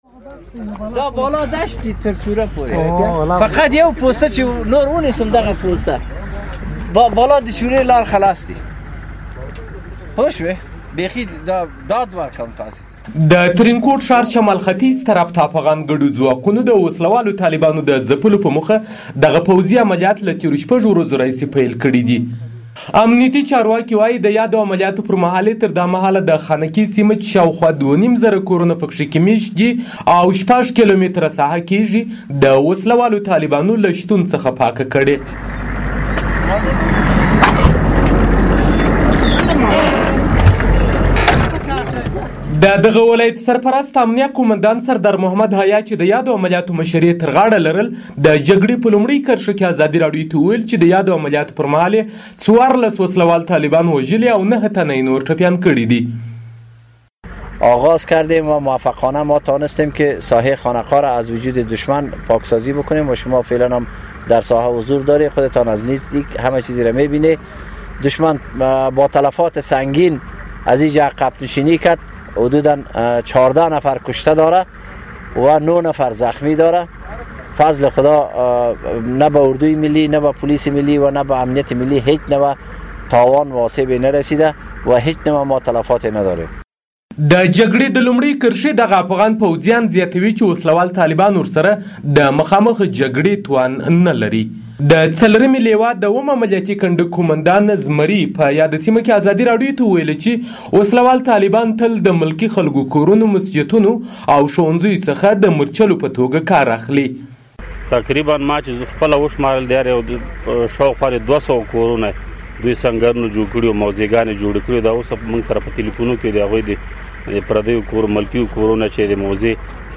ارزګان راپور